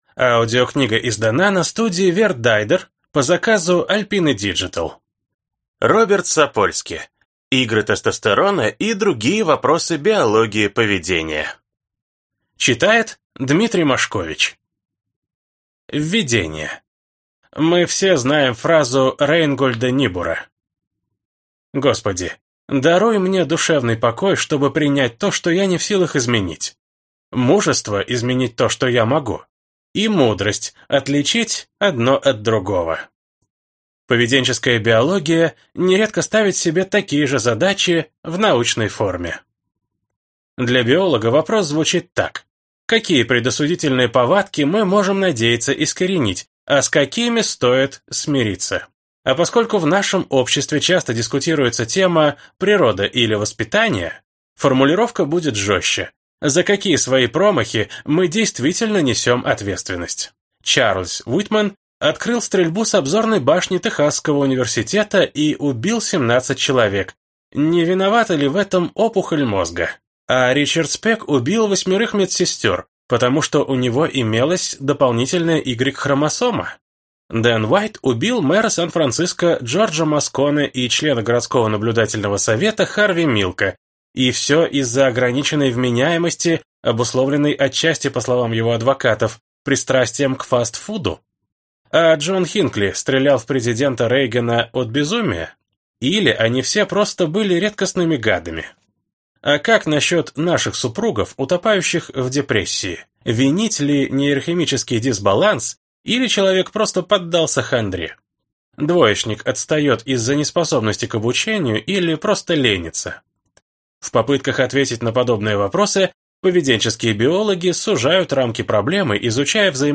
Аудиокнига Игры тестостерона и другие вопросы биологии поведения | Библиотека аудиокниг